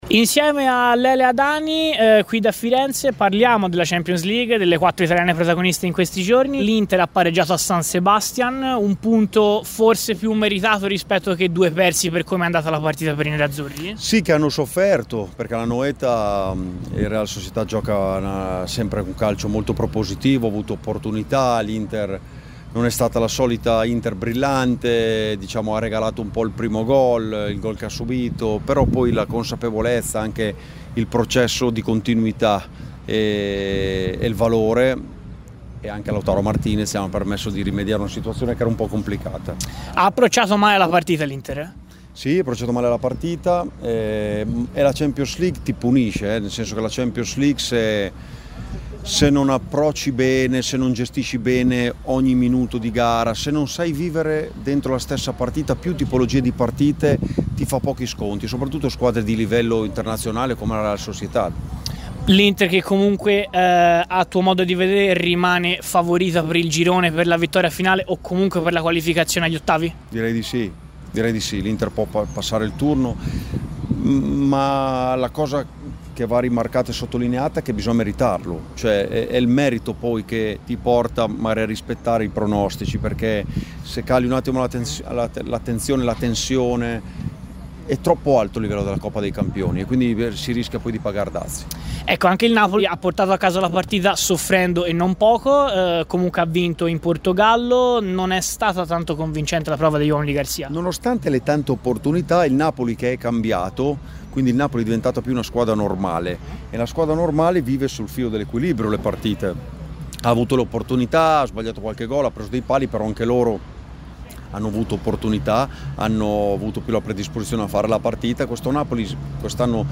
Nel frattempo, l’ex viola e oggi opinionista Daniele Adani, in esclusiva a Radio FirenzeViola, ha dato così uno sguardo generale sulla squadra di Italiano (l’intervista è stata realizzata a pochi minuti dal fischio d’inizio della gara col Genk, ndr).